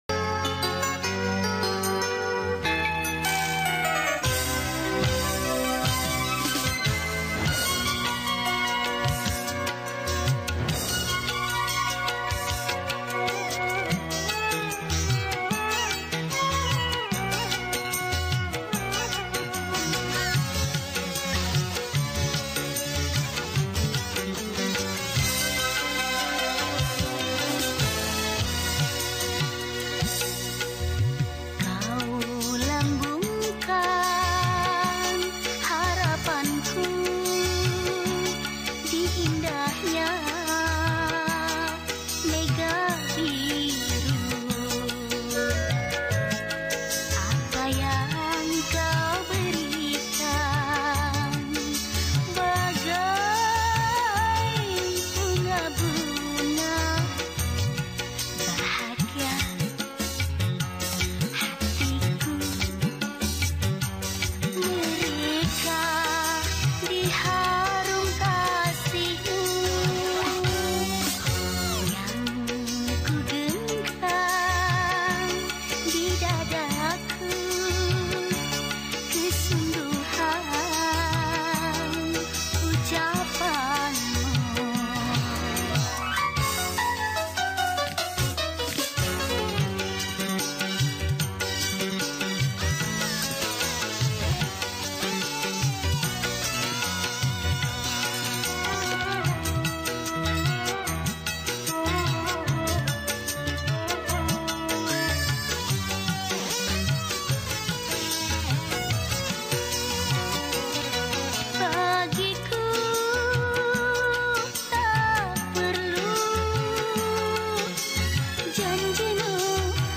Genre Musik                                       : Dangdut
Instrumen                                            : Vokal